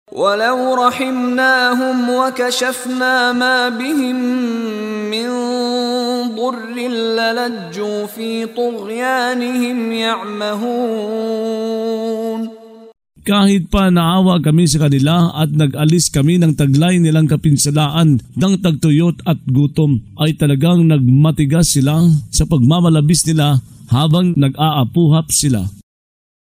Pagbabasa ng audio sa Filipino (Tagalog) ng mga kahulugan ng Surah Al-Mu'minun ( Ang Mga Sumasampalataya ) na hinati sa mga taludtod, na sinasabayan ng pagbigkas ng reciter na si Mishari bin Rashid Al-Afasy. Ang paglilinaw sa tagumpay ng mga mananampalataya atpagkalugi ng mga tagatangging sumampalataya.